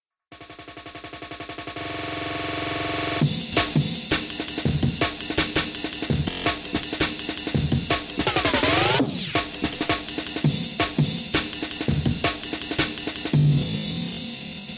This tutorial will outline many of the techniques you can use to transform a simple one-bar drum loop into a frenzy of percussive production wizardry.